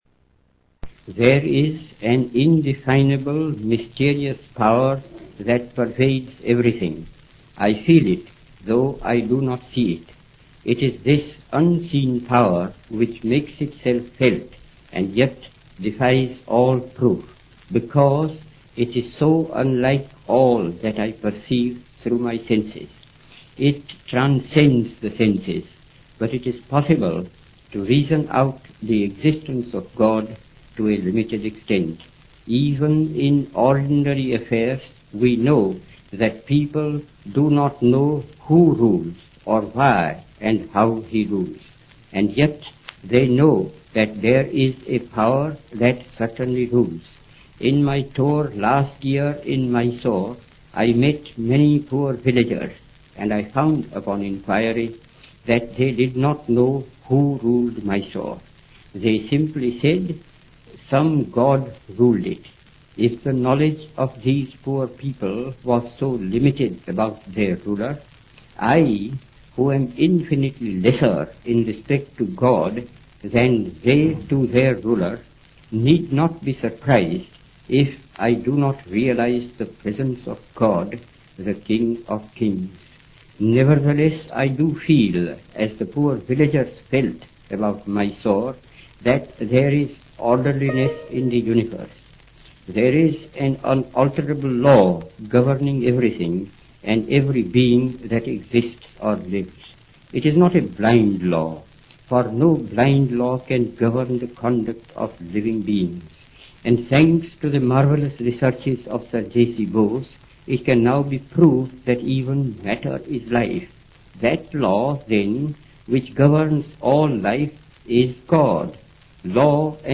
Gandhi - Spiritual Message (London, Kingsley Hall, 20 October 1931):
What follows, excluding the last sentence and the stanza from Newman, was recorded on October 20, 1931, by the Columbia Broadcasting Company, London, during Gandhiji’s stay in Kingsley Hall.